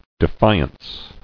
[de·fi·ance]